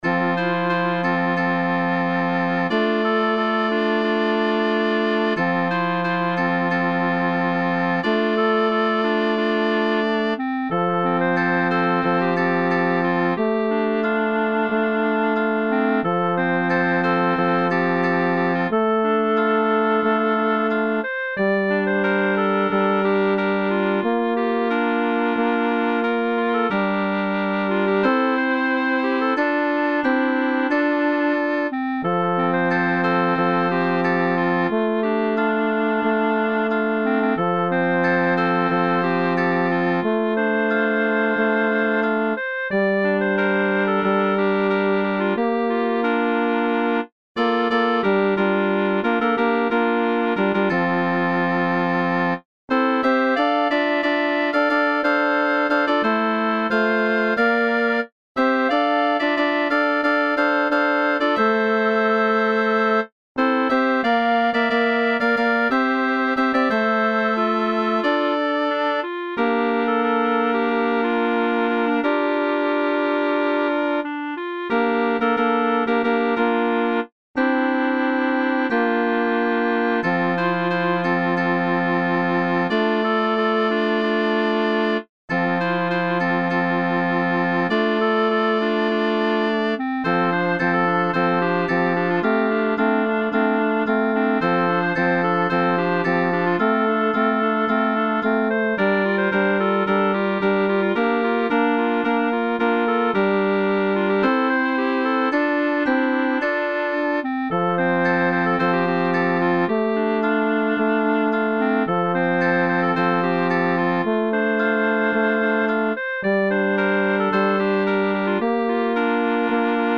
Upper Voices Performance